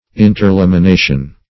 Interlamination \In`ter*lam`i*na"tion\, n. The state of being interlaminated.